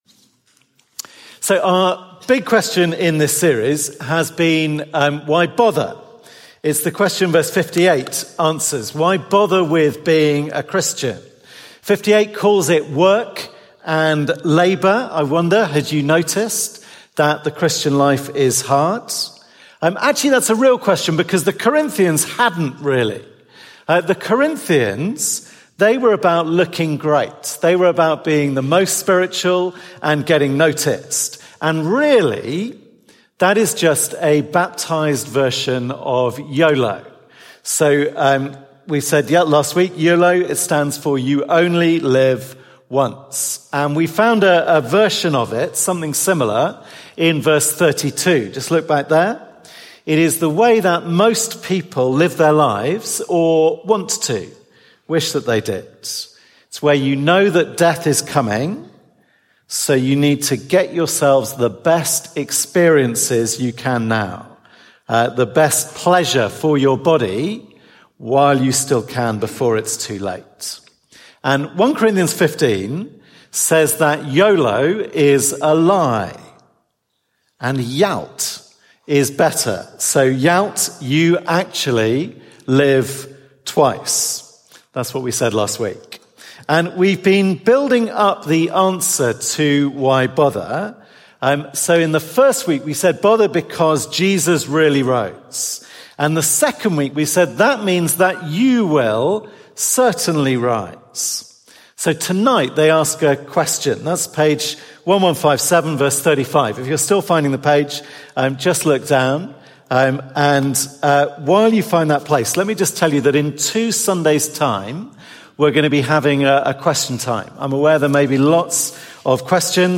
Sunday sermon feed from All Souls, Langham Place